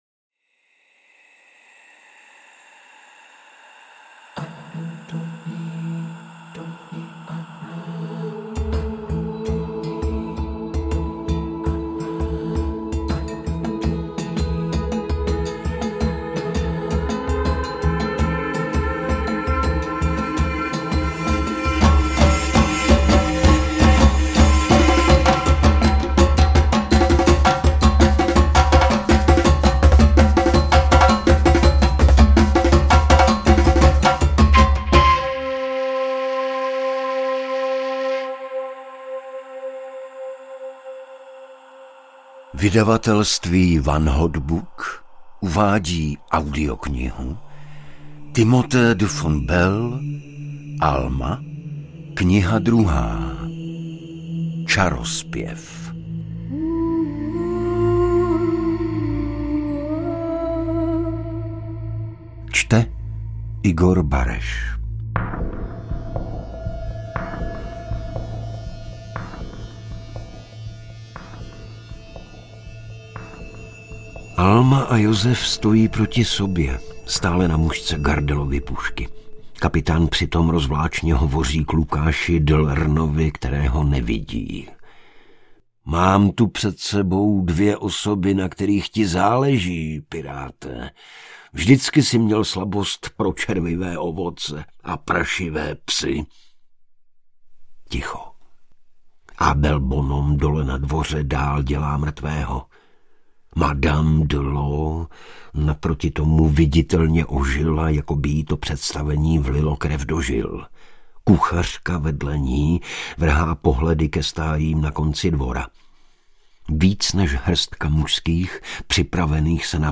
Alma II – Čarozpěv audiokniha
Ukázka z knihy
• InterpretIgor Bareš